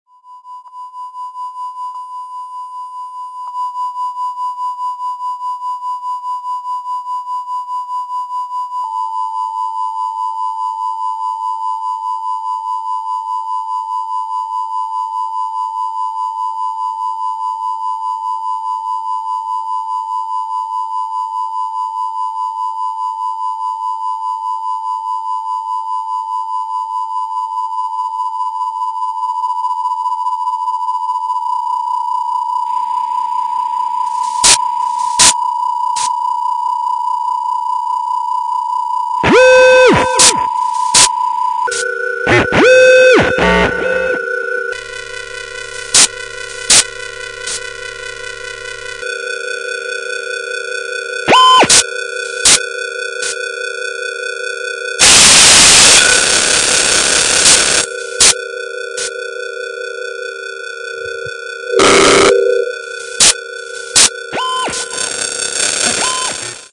on three tracks of neo-experimentalism
third pure sine waves .. &. bit of noise rhythm etc.